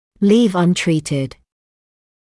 [liːv ʌn’triːtɪd][лиːв ан’триːтид]оставлять без лечения